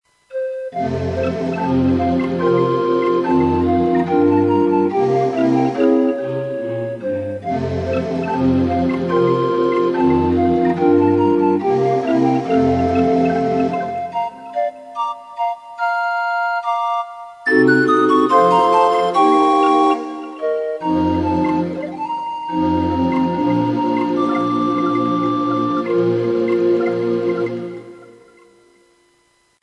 Ein 3 Mann Chor als Bäume getarnt stimmt das Lied an: